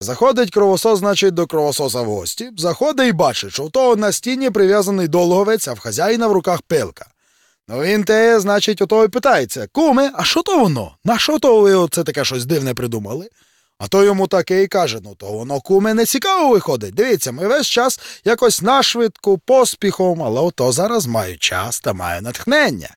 Копав файли Тіні Чорнобиля, відкопав озвучку, і курво: такі опрутненні жарти західняцьким діялектом, що пиздець.